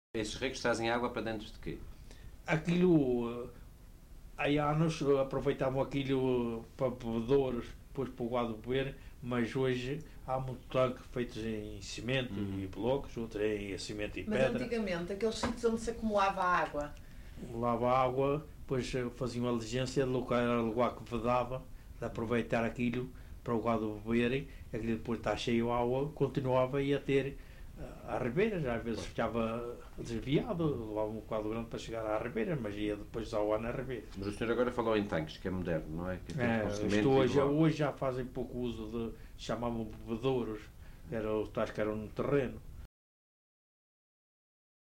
LocalidadeRibeira Seca (Calheta, Angra do Heroísmo)